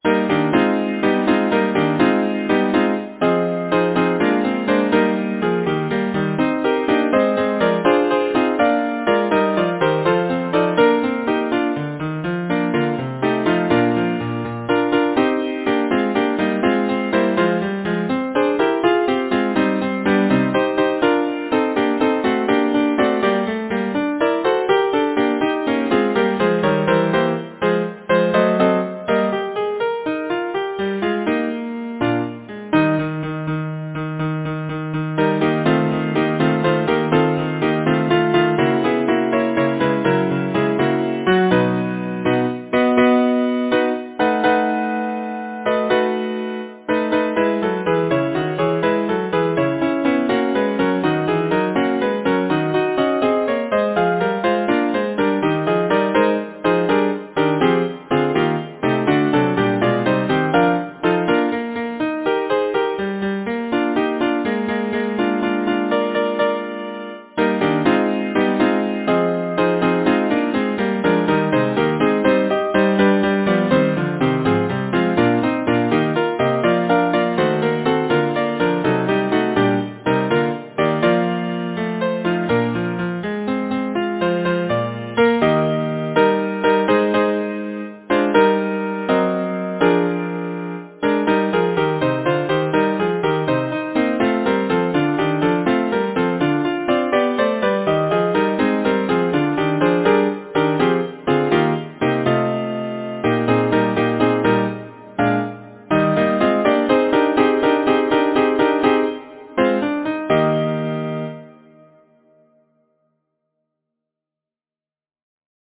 Title: Laughing Song Composer: Howard Carr Lyricist: William Blake Number of voices: 4vv Voicing: SATB Genre: Secular, Partsong
Language: English Instruments: A cappella